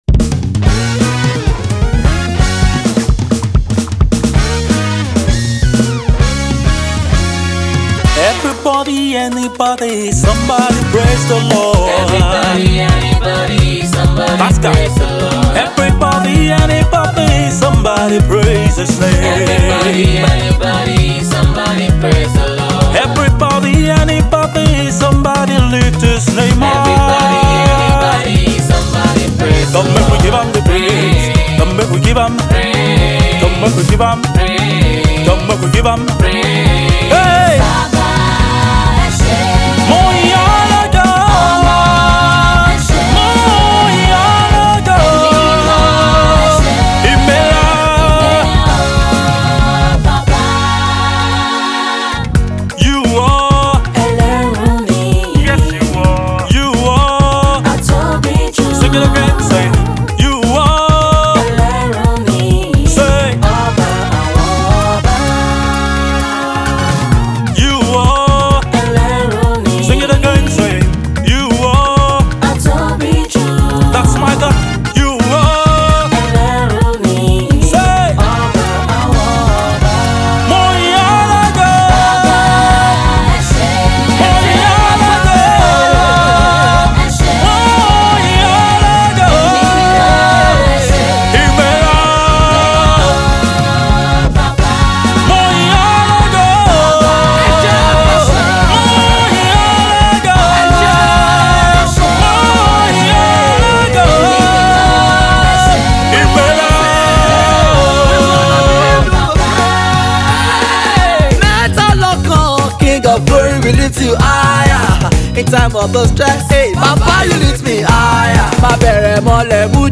praise hit single
African Praise
Nigerian gospel song